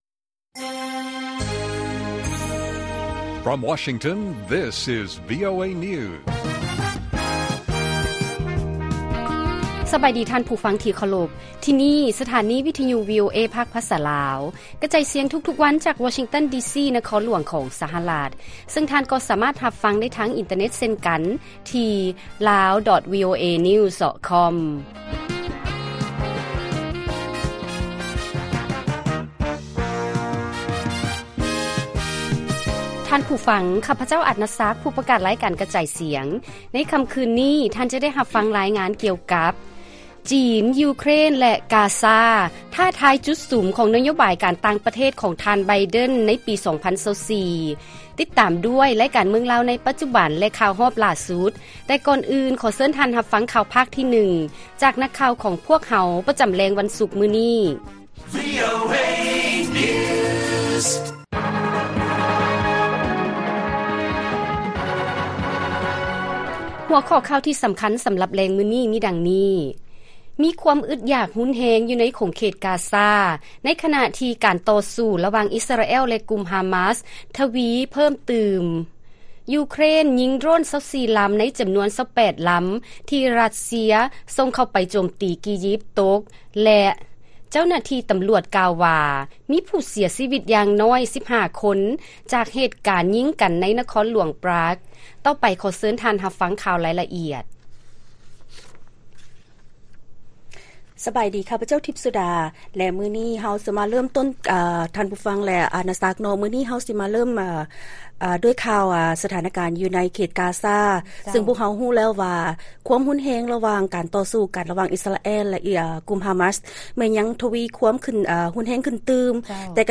ລາຍການກະຈາຍສຽງຂອງວີໂອເອ ລາວ: ມີຄວາມອຶດຢາກຮຸນແຮງຢູ່ໃນຂົງເຂດກາຊາ ໃນຂະນະທີ່ການຕໍ່ສູ້ ລະຫວ່າງ ອິສຣາແອລ ແລະ ກຸ່ມຮາມາສ ທະວີຂຶ້ນຕື່ມ